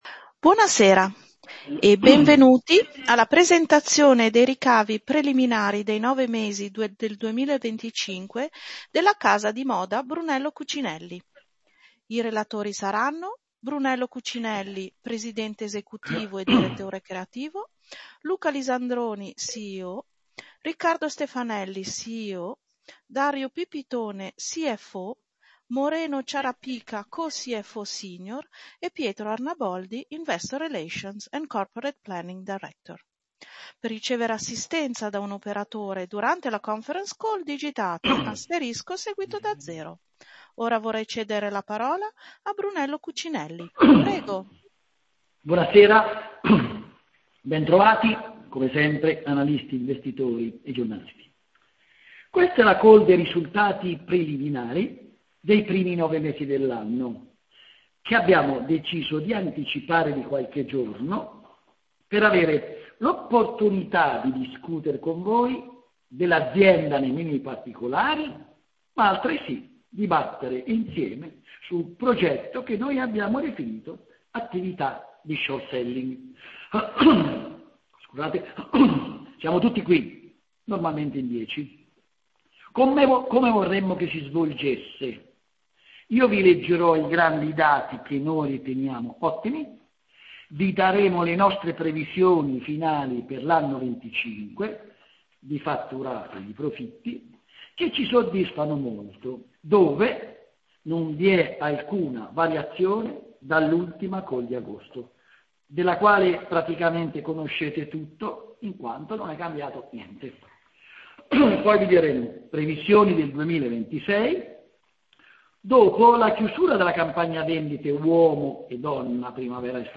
Conference_call_Ricavi_Preliminari_9M_2025.mp3